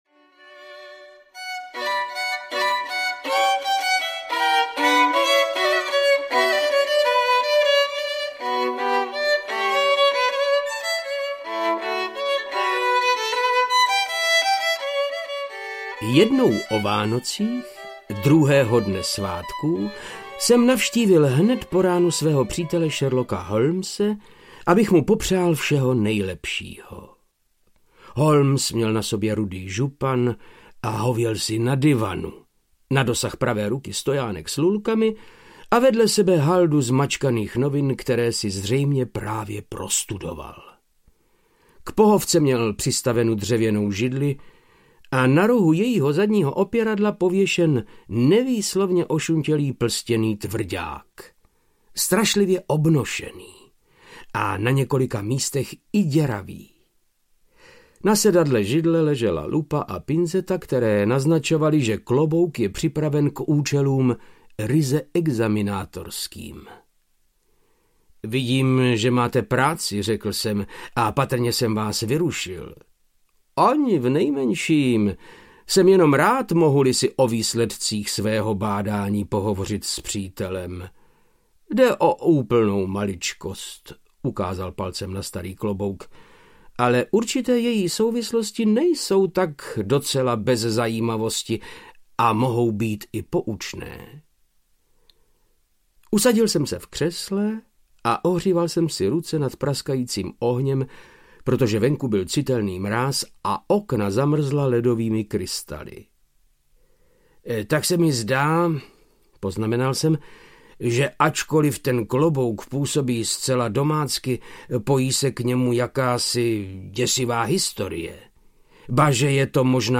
Modrá karbunkule audiokniha
Ukázka z knihy
• InterpretVáclav Knop